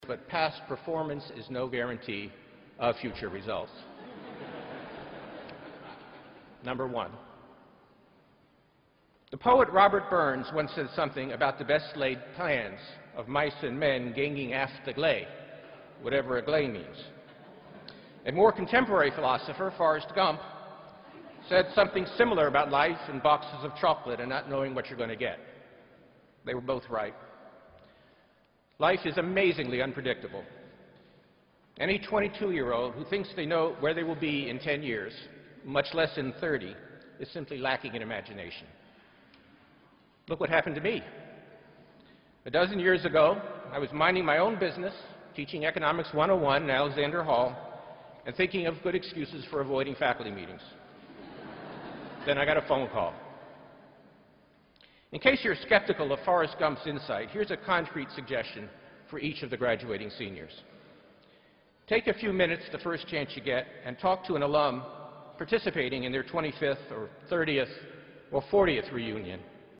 公众人物毕业演讲第299期:本伯南克2013普林斯顿(3) 听力文件下载—在线英语听力室